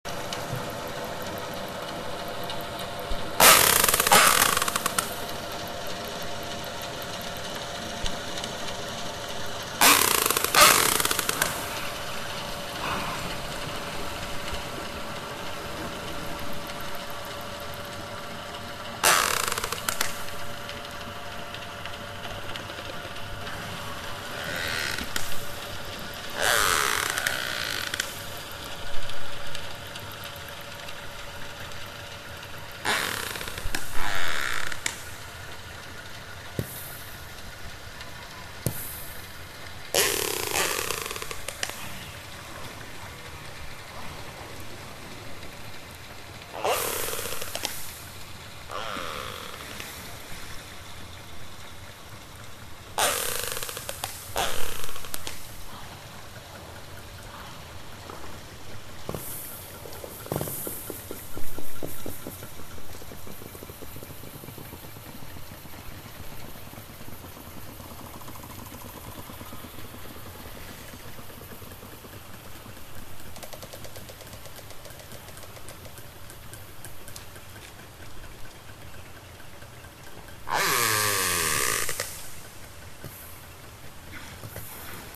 ملف:Killer whale residents broadband.ogg - المعرفة
Killer_whale_residents_broadband.ogg.mp3